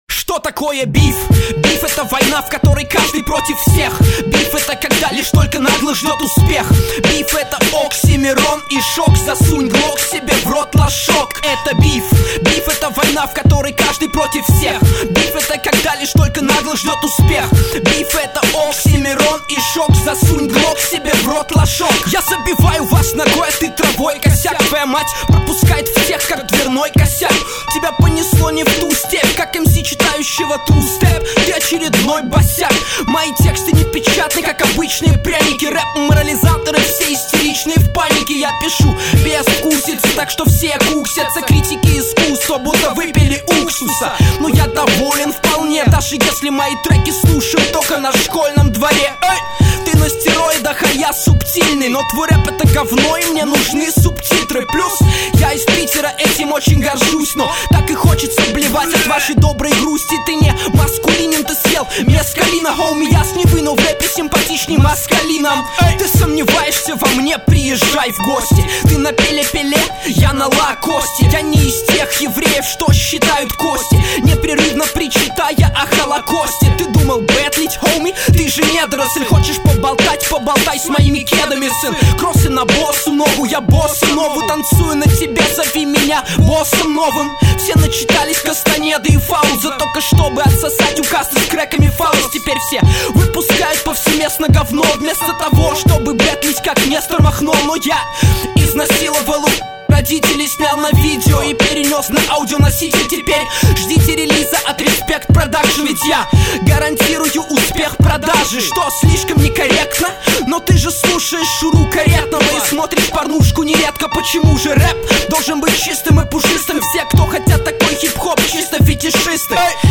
Охуенно зачитали оба)
эта тема расслабон
минус не резали а как был